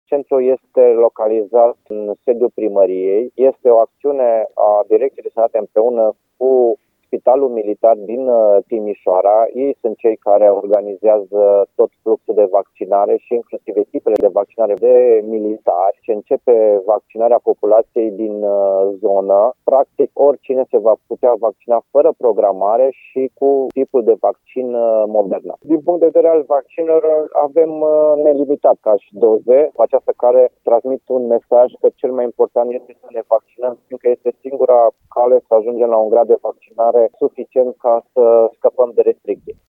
Directorul Direcției de Sănătate Publică Arad, Horea Timiș, spune că în noul centru de vaccinare se va putea imuniza orice persoană interesată, fără să aibă nevoie de programare prealabilă.
Horea-Timis-vaccinare-Halmagiu.mp3